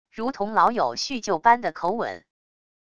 如同老友叙旧般的口吻wav音频